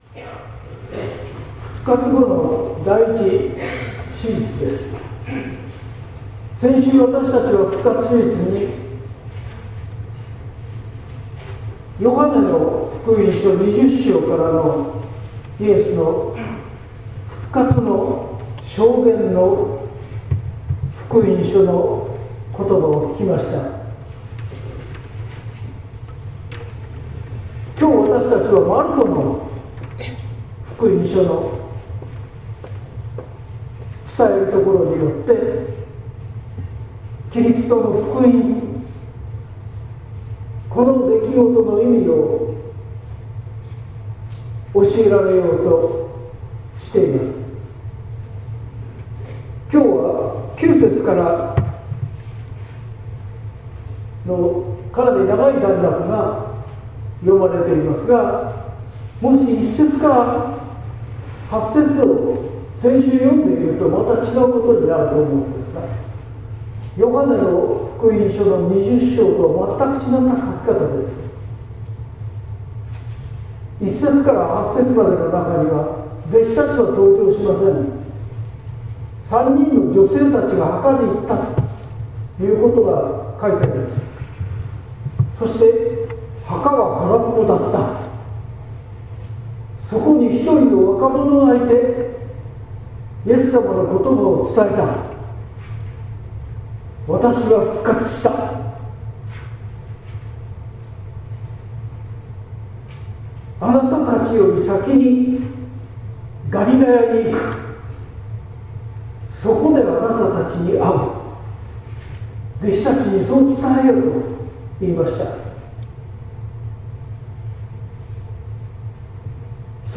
説教「弟子たちの『福音の初め』」（音声版）